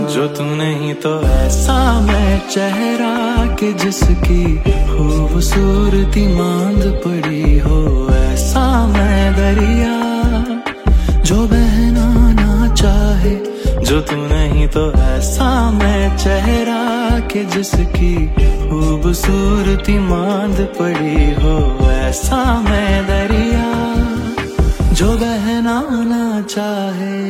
modern yet soulful touch